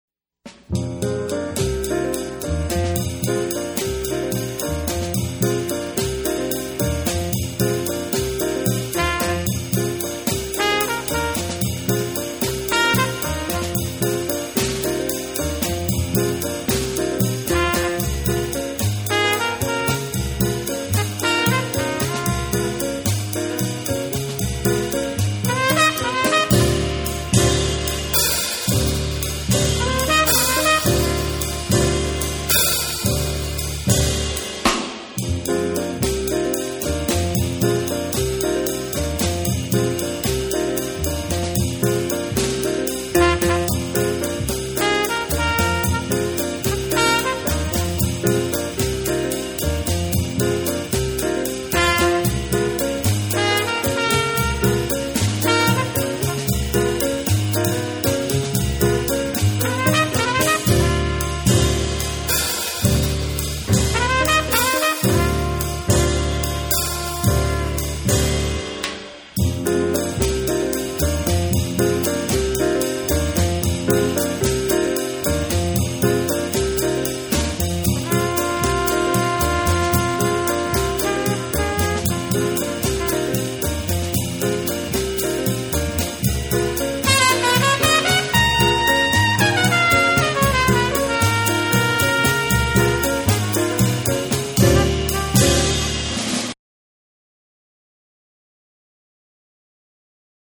Jazz
Standard du jazz